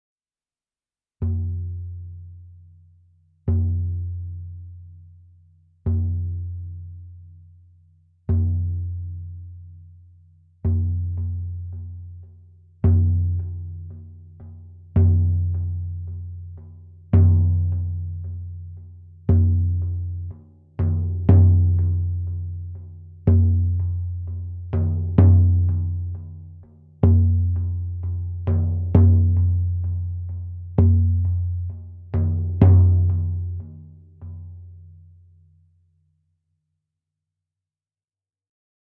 The Meinl Sonic Energy Ritual Drums guarantee a remarkable resonance that engrosses your senses during sound baths, grounding, or musical sessions.…